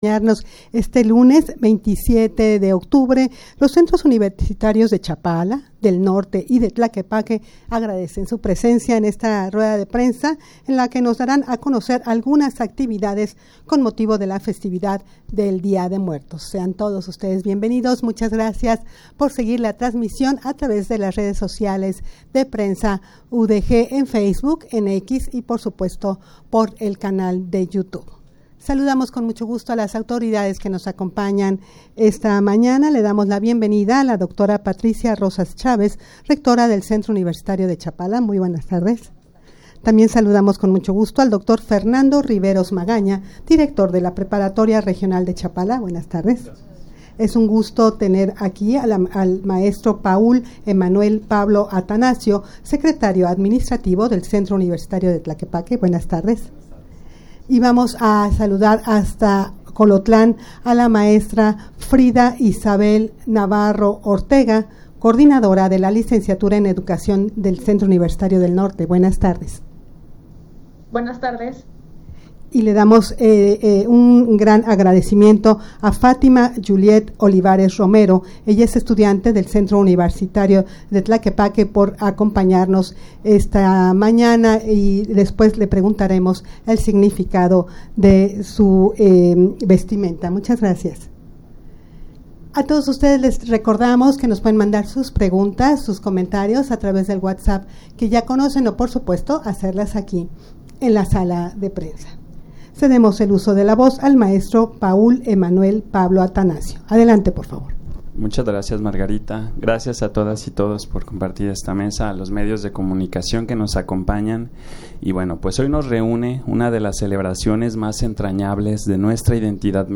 Audio de la Rueda de Prensa
rueda-de-prensa-para-dar-a-conocer-sus-actividades-con-motivo-de-la-festividad-del-dia-de-muertos.mp3